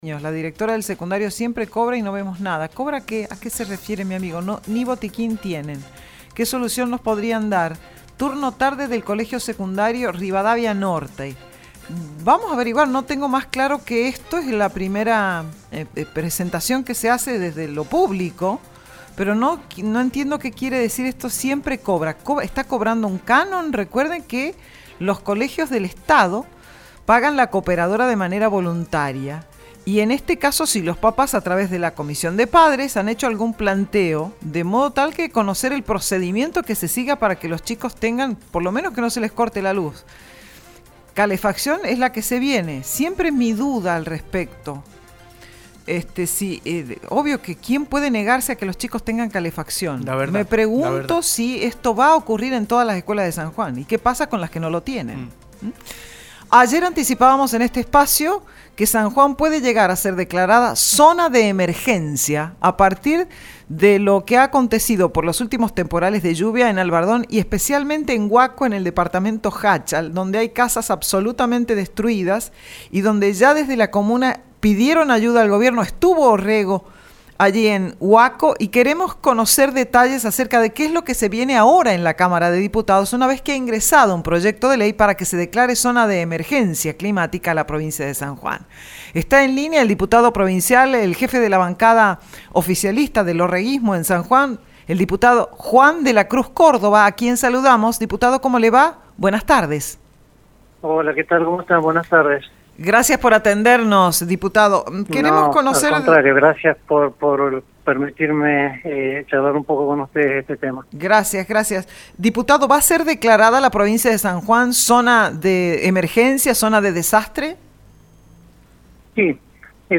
Las declaraciones a ESTACION CLARIDAD las realizó el Diputado Provincial Juan de la Cruz Córdoba.